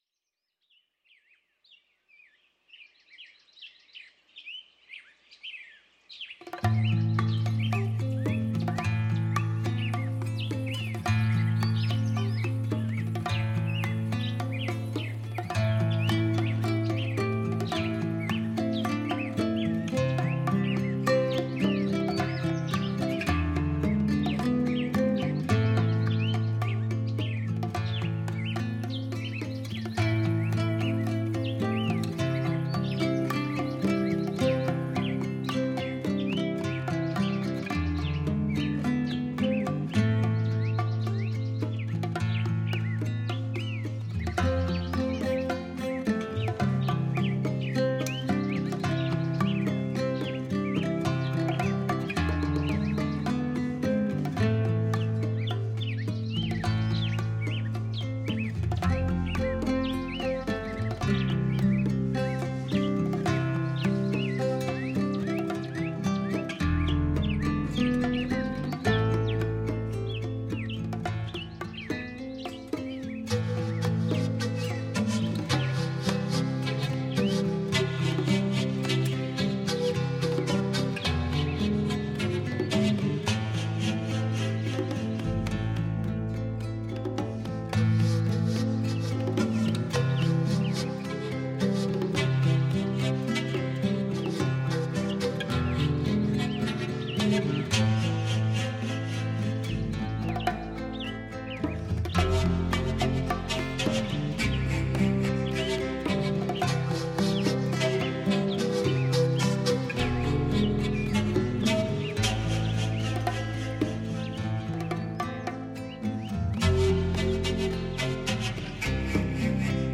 最精彩新颖南美风格乐曲,发烧敲击排笛乐队及太阳之勇! 最棒的节奏感!
我们可以看到众多部落和南美风格音乐的作 者的作品，再一次感受到节奏音乐的强劲音律！